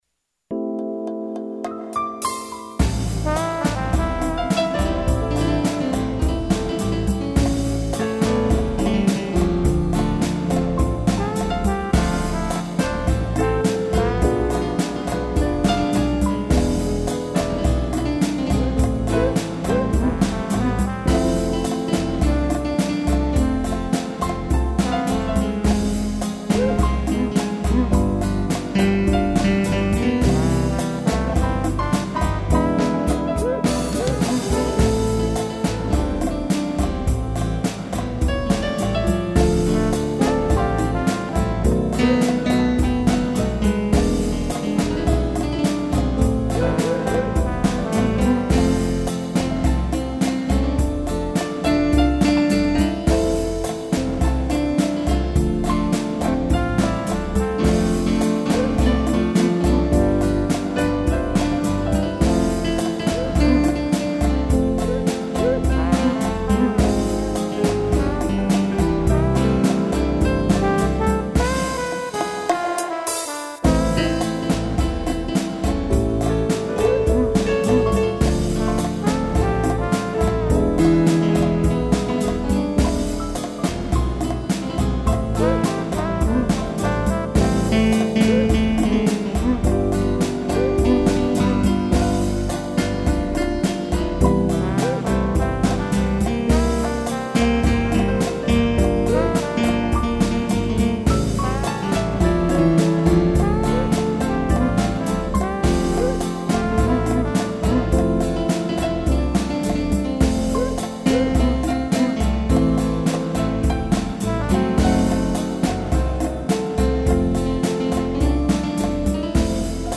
piano, trombone, cuíca e tamborim